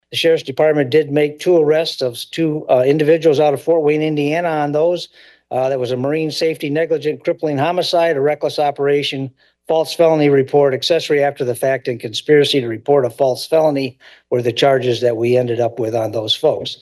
Sheriff Pollack gives final report to Coldwater City Council